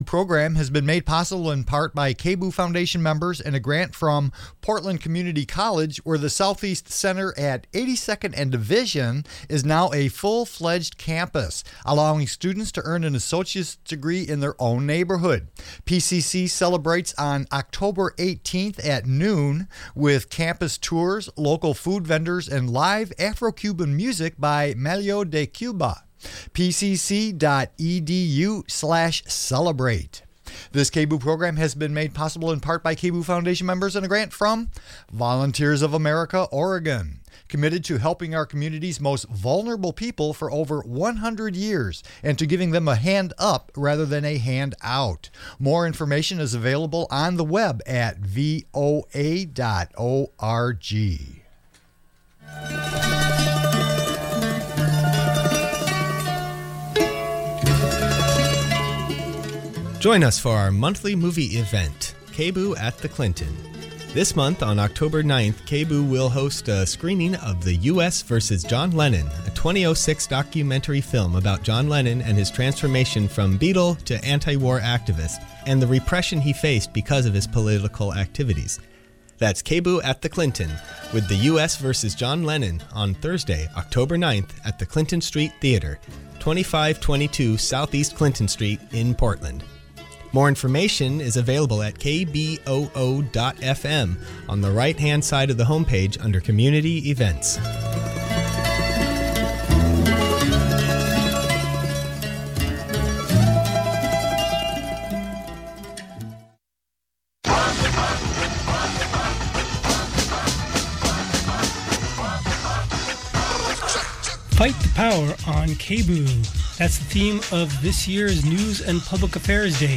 Call in and share stories about repentance, forgiveness and reconciliation.